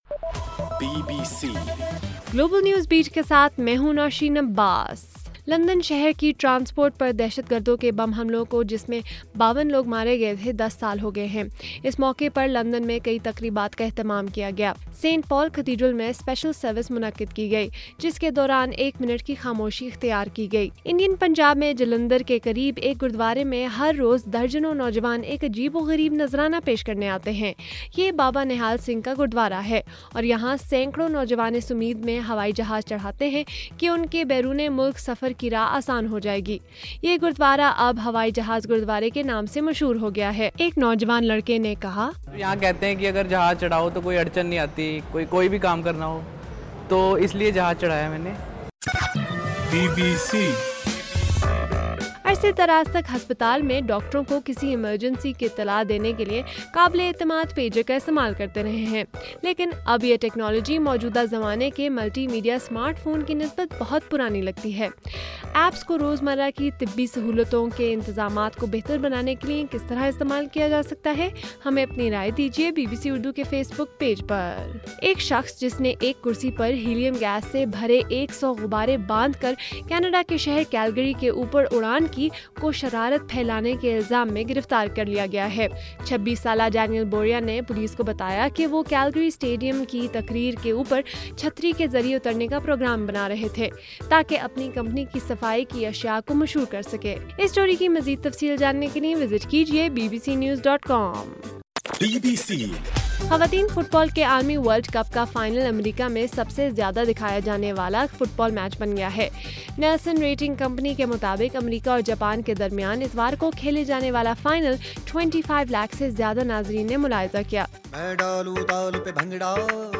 جولائی 8: صبح1بجے کا گلوبل نیوز بیٹ بُلیٹن